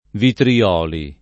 [ vitri- 0 li ]